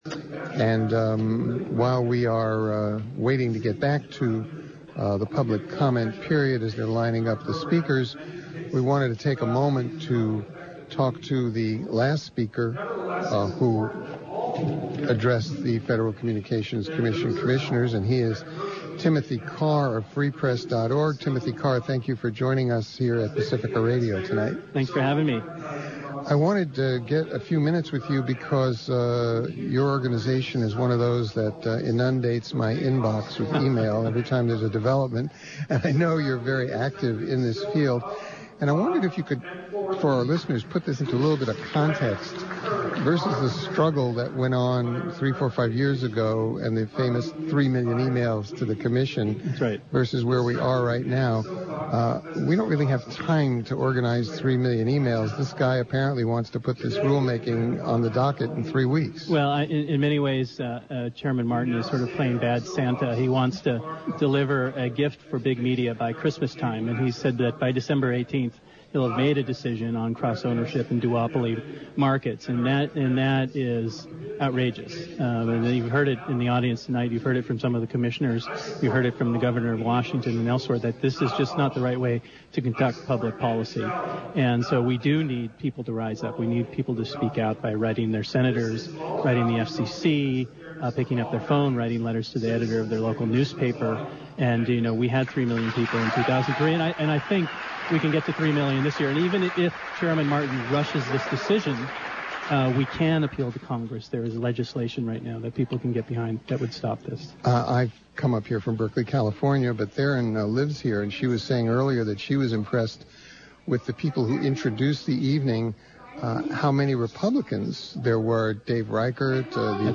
KPFA, 94.1FM in Berkeley, aired the proceedings live.